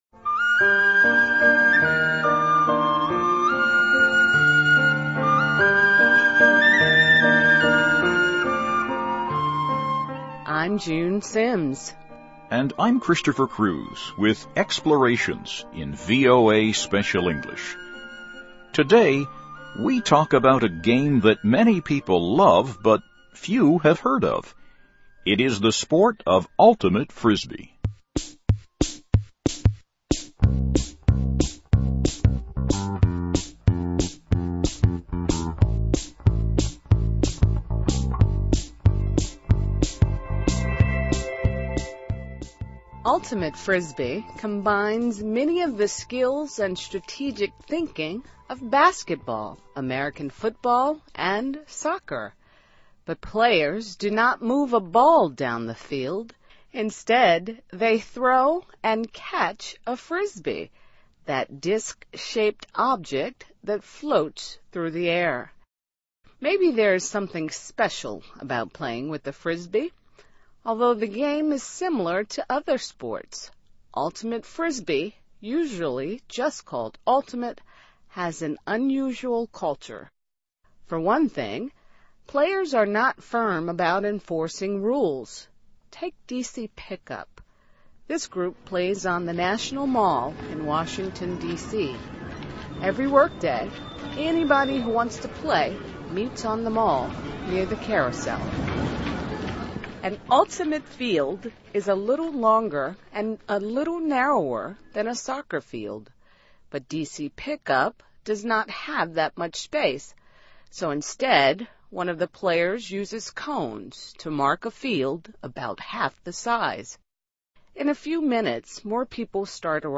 SOUND: Barking dog